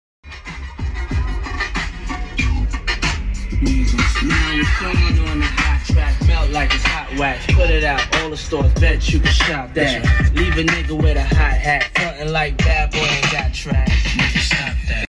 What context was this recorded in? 🎶🔊 This one-of-a-kind Road Glide is a true masterpiece. It's decked out with speakers everywhere, delivering an incredible audio experience as you hit the open road.